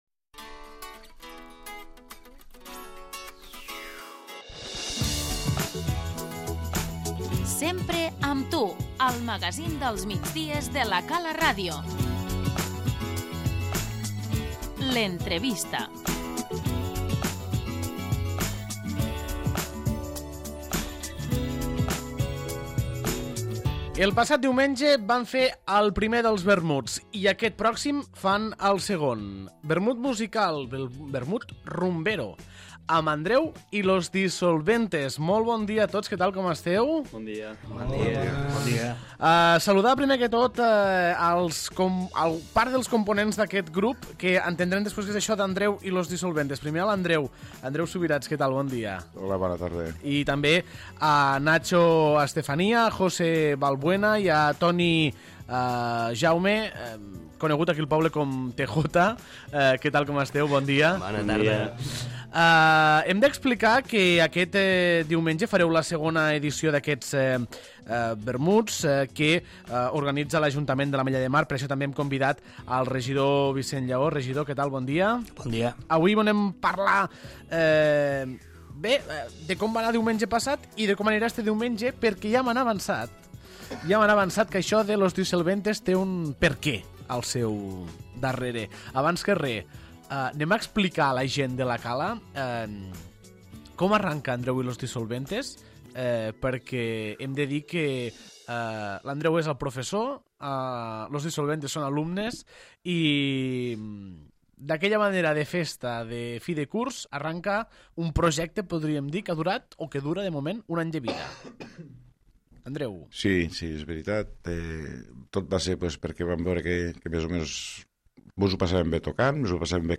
L'entrevista - Andreu & Los Disolventes
Aquest diumenge al migdia tens una cita a la plaça Nova per fer el vermut amb Andreu & Los Disolvente. Avui ens acompanyen per explicar-nos els orígens d'aquest grup part dels seus components, i també el regidor de Festes, Vicenç Llaó, per explicar-nos aquesta proposta musical.